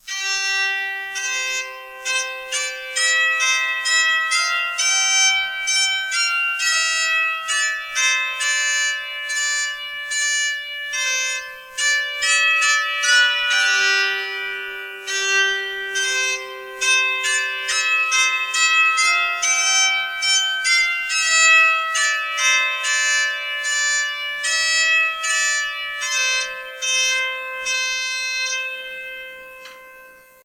The Bowed Psaltery
They have the most wonderful ringing sound of any musical instrument that I’ve ever heard, with a haunting sustain that makes the music seemingly float in midair and tingle in your ears.